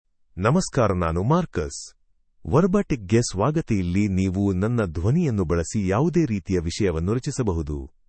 MaleKannada (India)
MarcusMale Kannada AI voice
Voice sample
Marcus delivers clear pronunciation with authentic India Kannada intonation, making your content sound professionally produced.